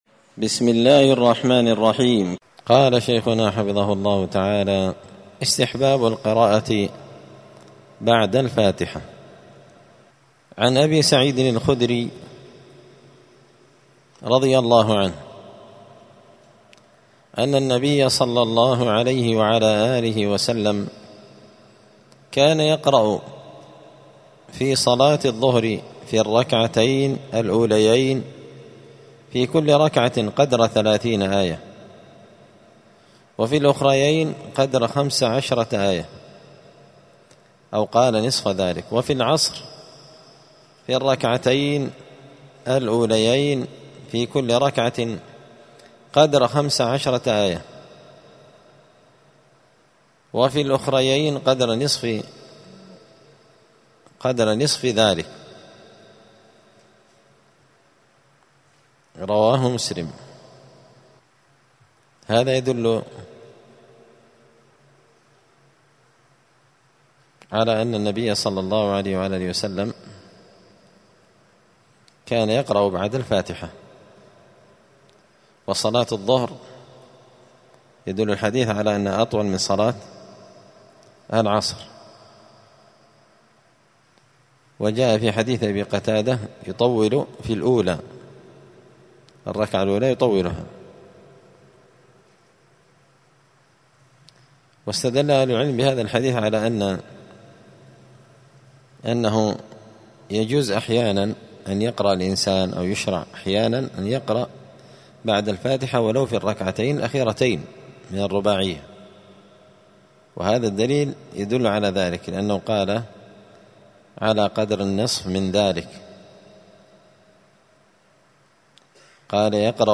*{الدرس الثامن والعشرون (28) أذكار الصلاة استحباب القراءة بعد الفاتحة}*
28الدرس-الثامن-والعشرون-من-كتاب-الاختيار-لأهم-صحاح-الأذكار.mp3